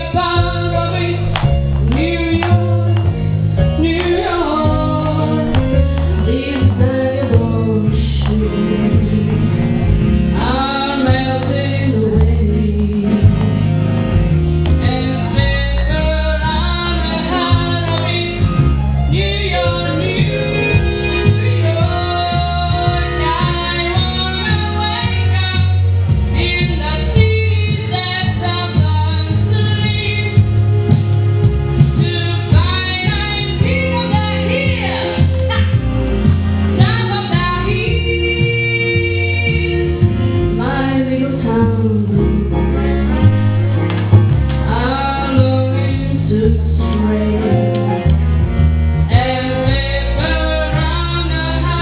Il musical - Pontelagoscuro 6 giugno 2005
musica